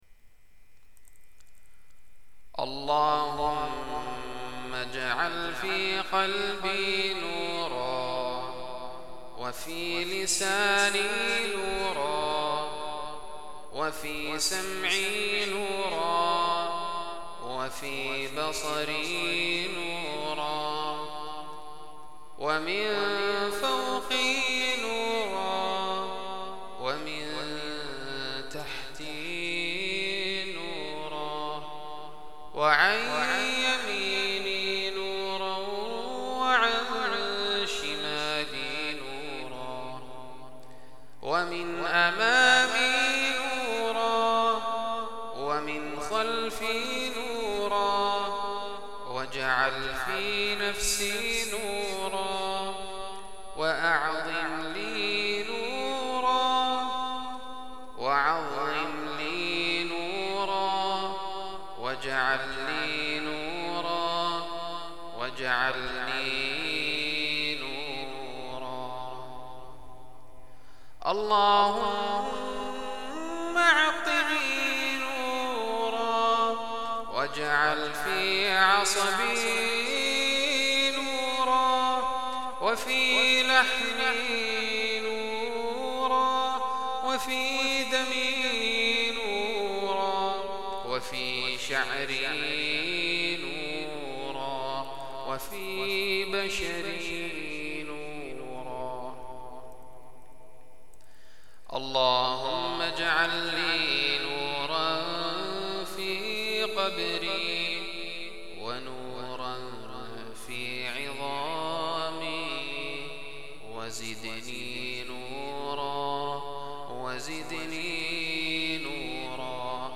تلاوة لدعاء الذهاب إلى المسجد